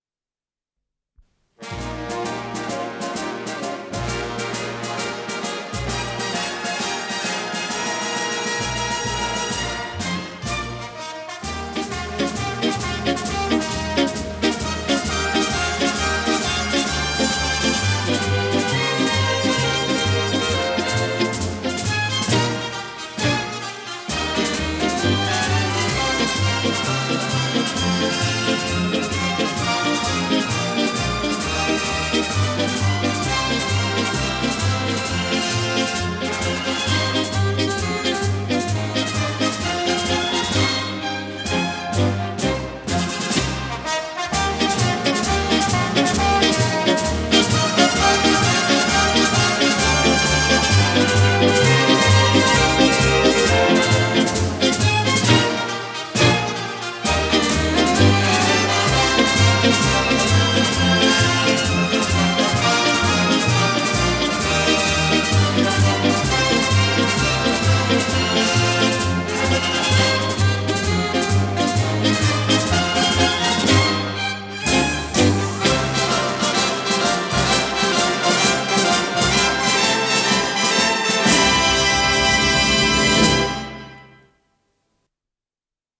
с профессиональной магнитной ленты
ПодзаголовокЗаставка
ВариантМоно